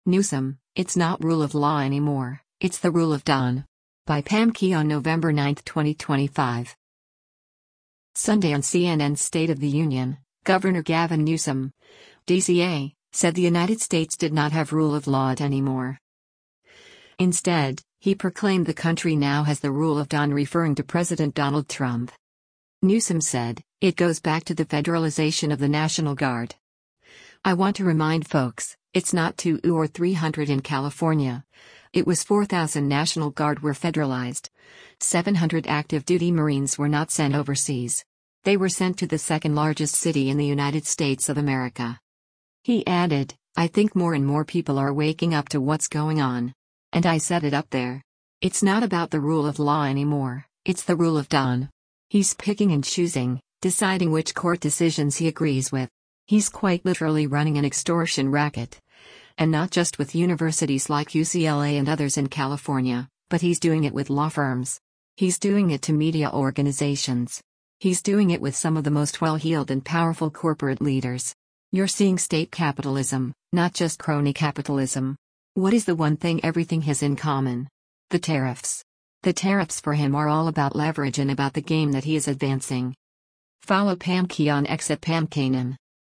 Sunday on CNN’s “State of the Union,” Gov. Gavin Newsom (D-CA) said the United States did not have rule of law it anymore.